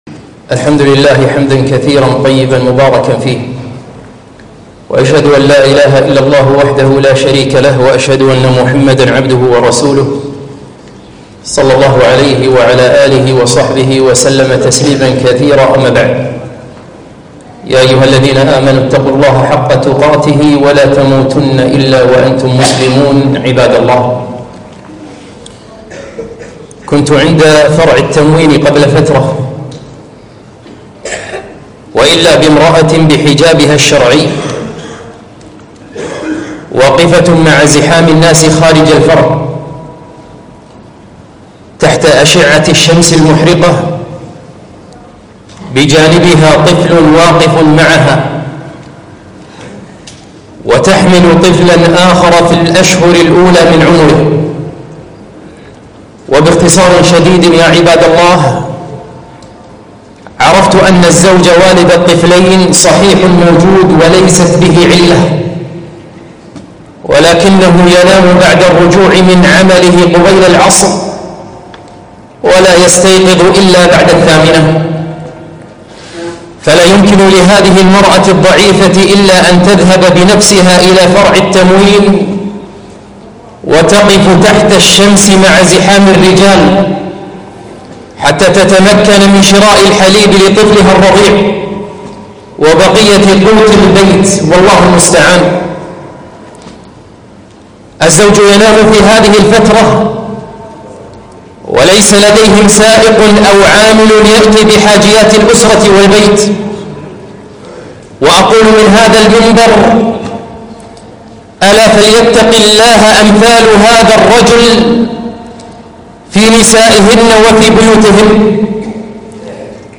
خطبة - أشباه الأزواج